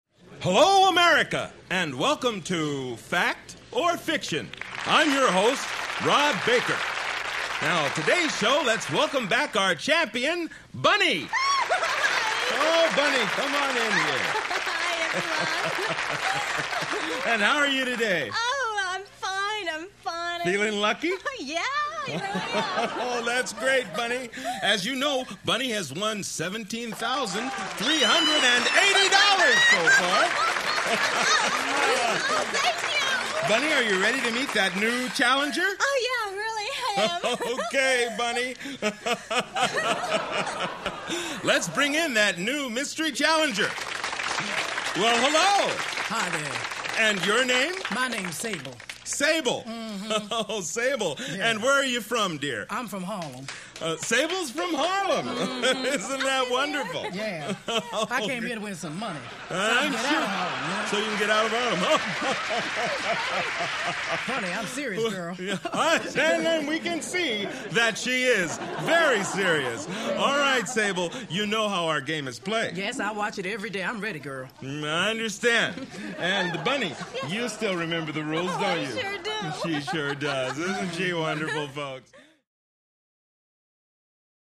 Television; Full Game Show With Announcer, Crowd And Players. From Close.